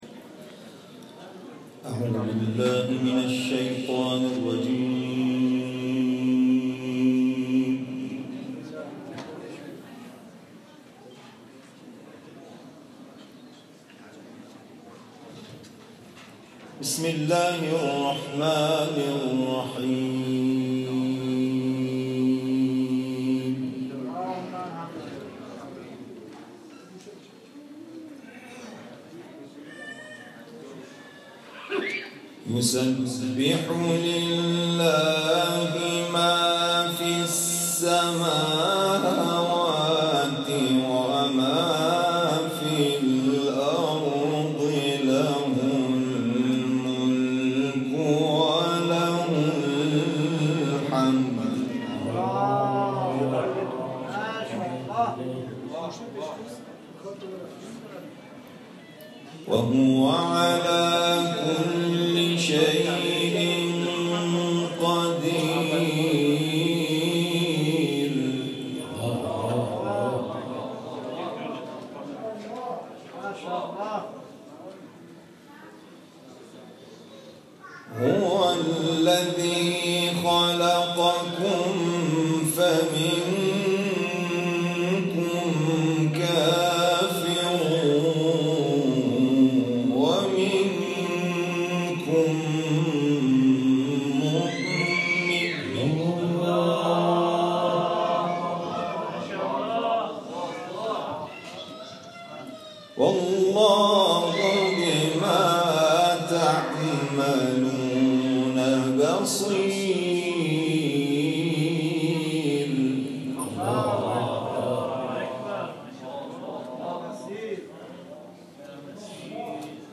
جدیدترین تلاوت
از سوره مبارکه تغابن که در مسجد الجلیل اجرا شده است